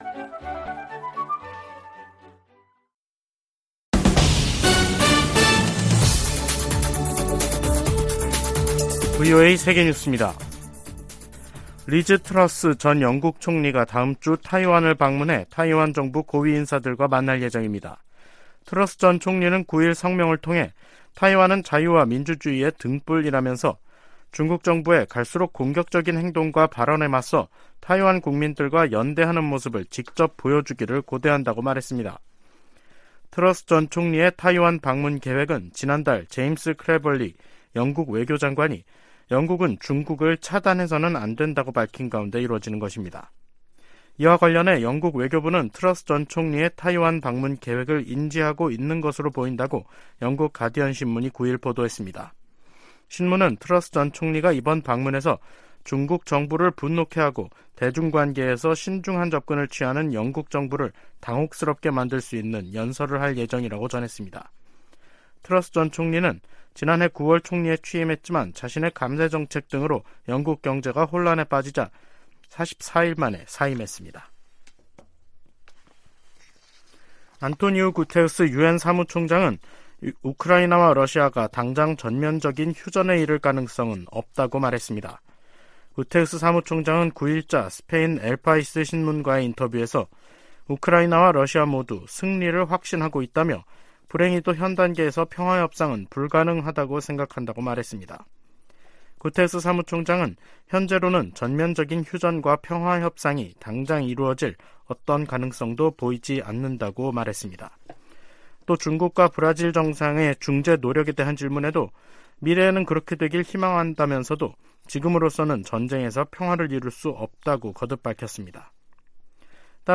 VOA 한국어 간판 뉴스 프로그램 '뉴스 투데이', 2023년 5월 9일 3부 방송입니다.